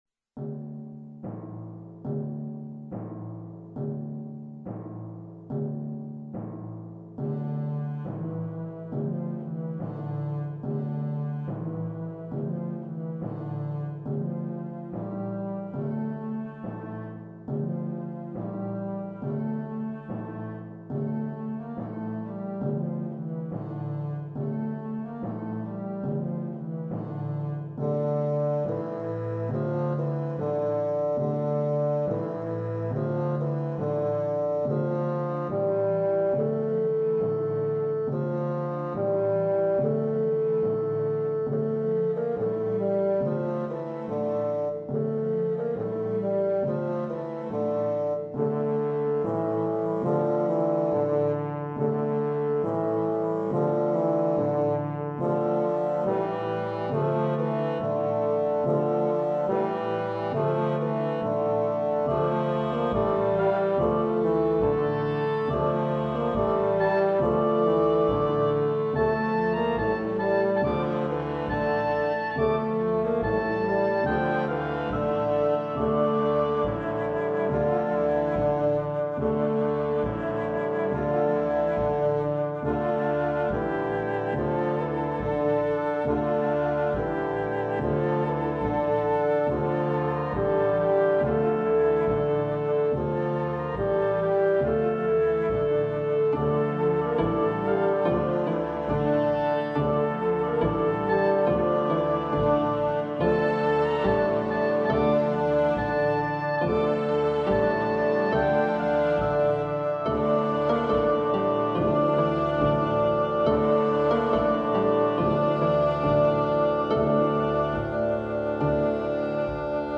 La marcia funebre è distante da qualsiasi finalità celebrativa: una semplice melodia popolare, trasportata in modo minore, ad esprimere il senso di una morte anonima, inutile.
La proponiamo in versione didattica per flauto.